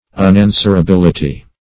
Search Result for " unanswerability" : The Collaborative International Dictionary of English v.0.48: Unanswerability \Un*an`swer*a*bil"i*ty\, n. The quality of being unanswerable; unanswerableness.
unanswerability.mp3